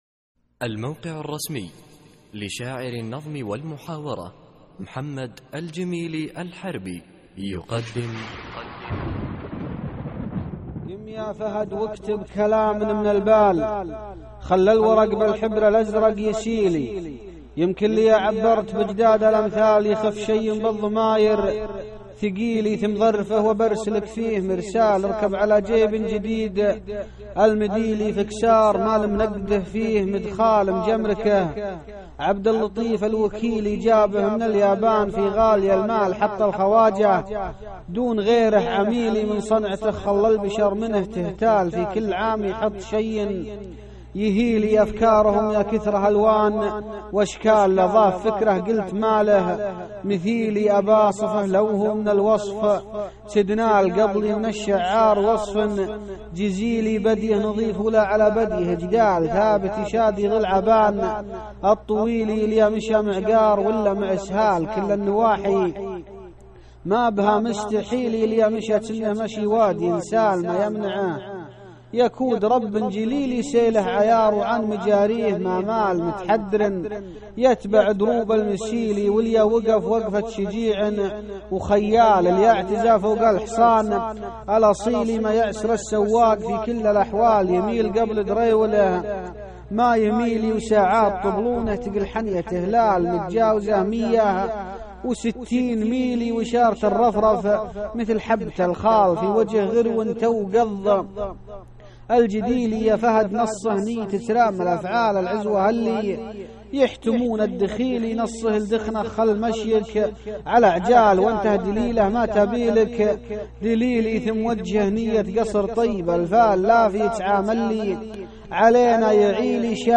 القصـائــد الصوتية
اسم القصيدة : لافي كعام اللي علينا يعلي ~ إلقاء